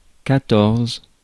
Ääntäminen
Ääntäminen France: IPA: [ka.tɔʁz] Haettu sana löytyi näillä lähdekielillä: ranska Käännöksiä ei löytynyt valitulle kohdekielelle.